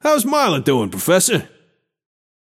Shopkeeper voice line - How’s Marlowe doing, Professor?